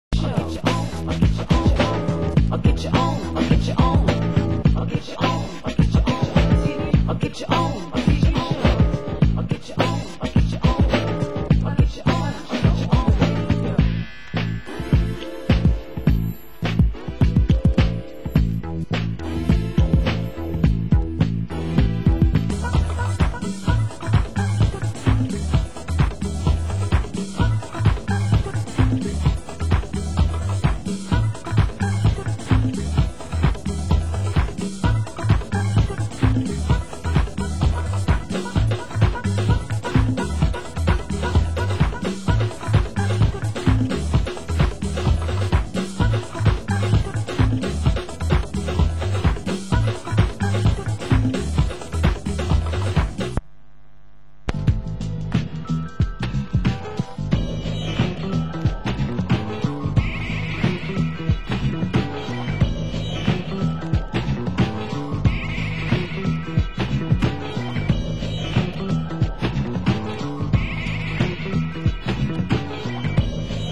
Genre: House
Genre: Electronica